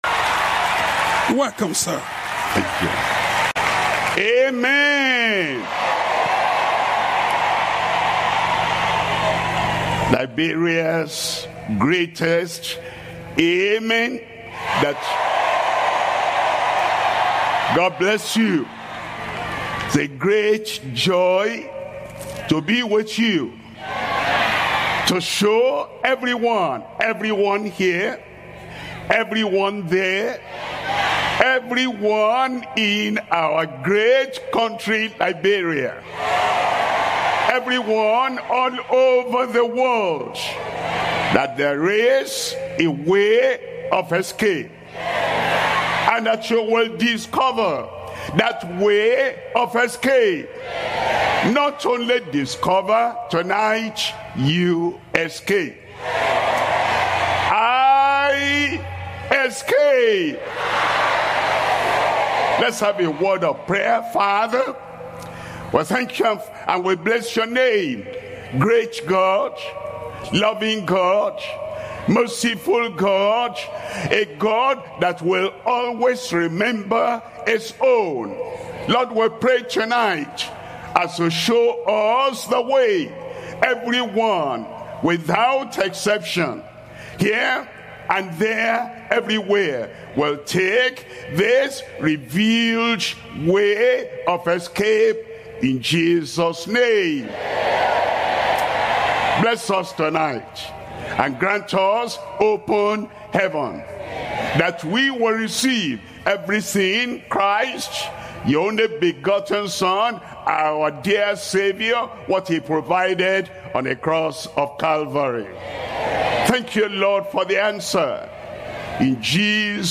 Sermons - Deeper Christian Life Ministry